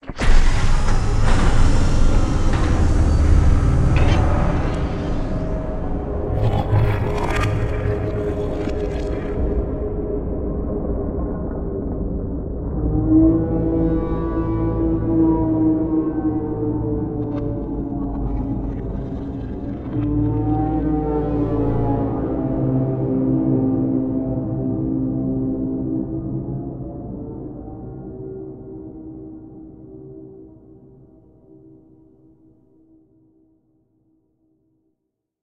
Gun_shutdown_sfx.ogg